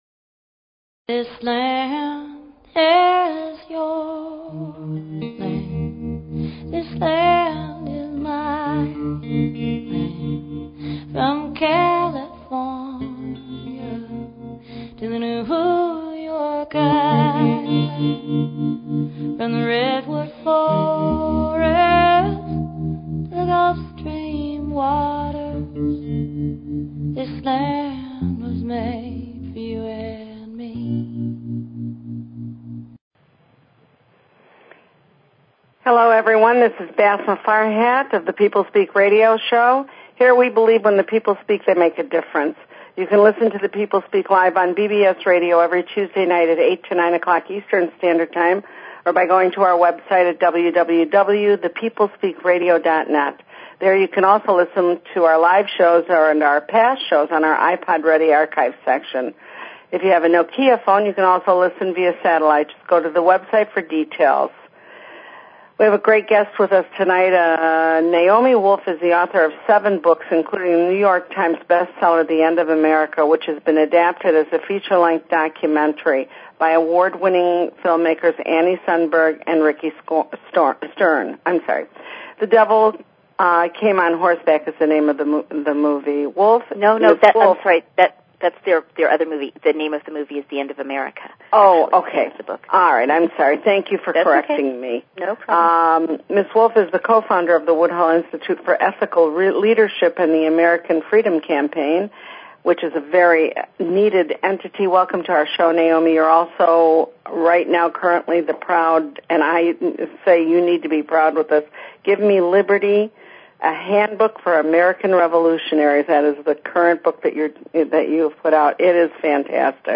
Guest, Naomi Wolf